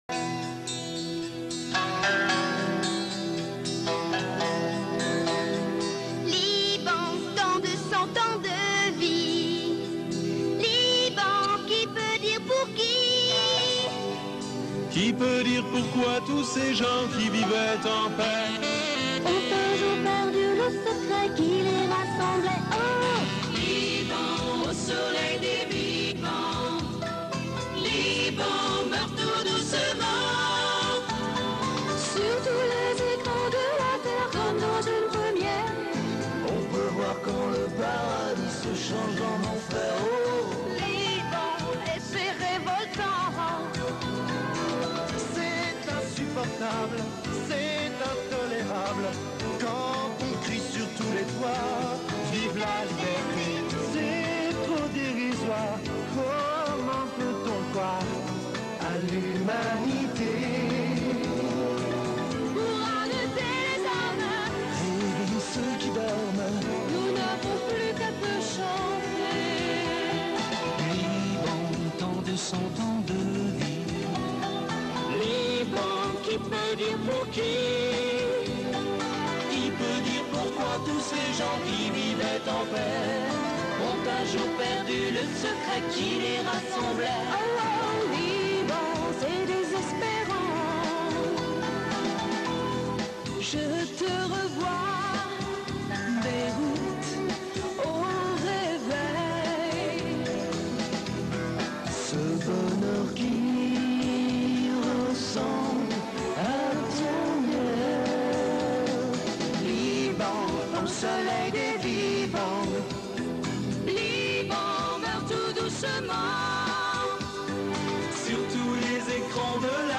plusieurs artistes ont chanté en choeur pour aider la cause